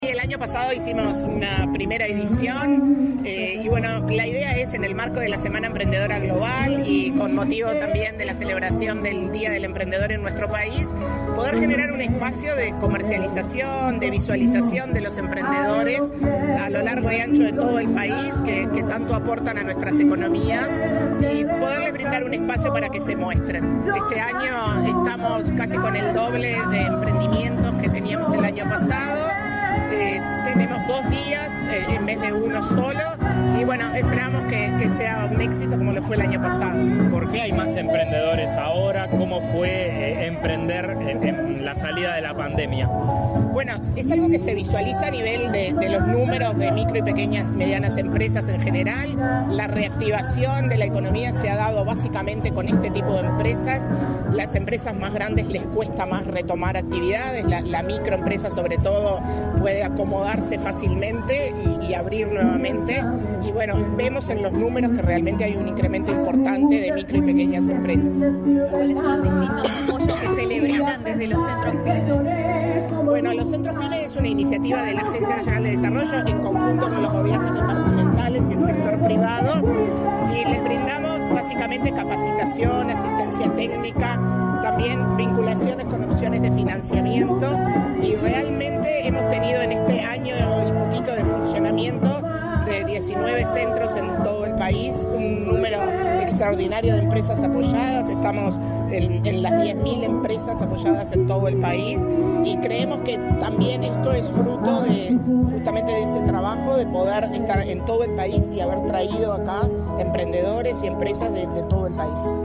Declaraciones a la prensa de la presidenta de ANDE, Carmen Sánchez
Tras participar en la feria de emprendimientos, en el marco del Día Nacional de la Cultura Emprendedora, organizada por la Agencia Nacional de